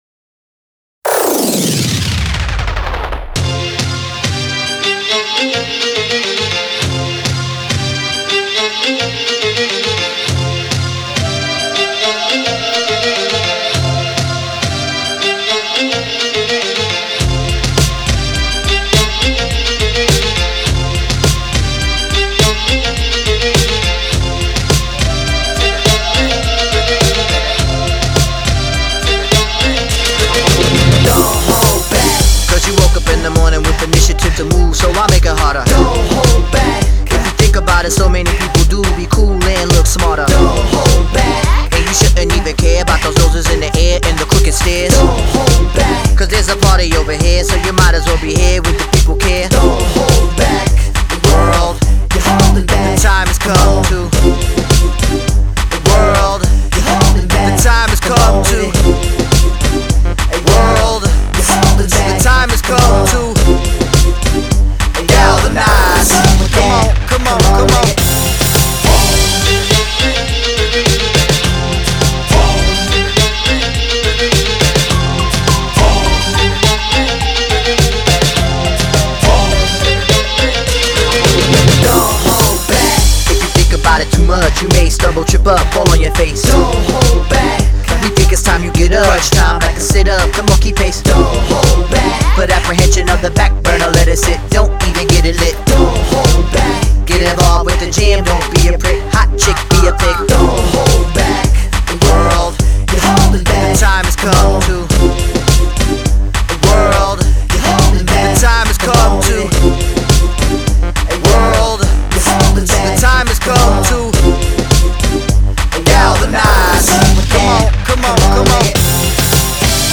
BPM104-104
Audio QualityPerfect (High Quality)
Breakbeat song for StepMania, ITGmania, Project Outfox
Full Length Song (not arcade length cut)